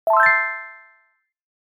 notification-sound.mp3